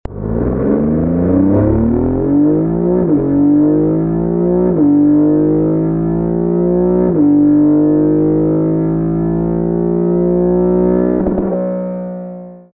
EMIRA INTERIOR - World's best active sound